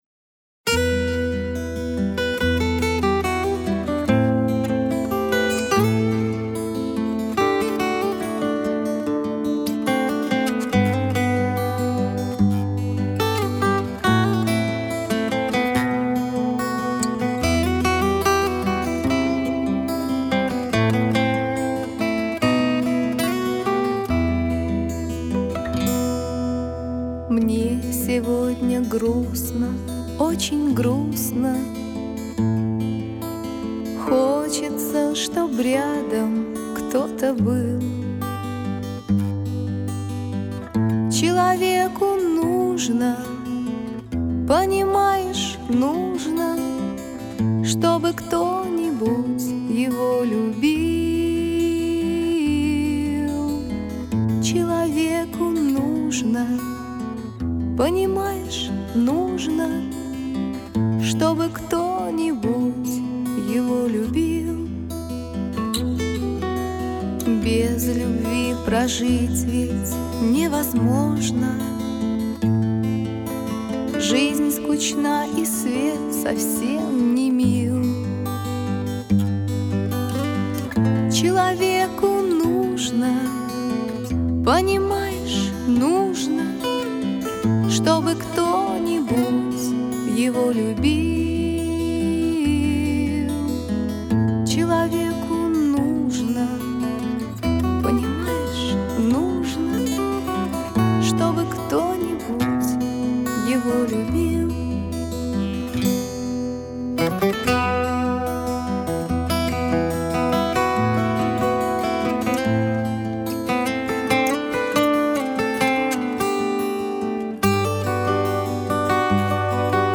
Лирические
Слушать на гитаре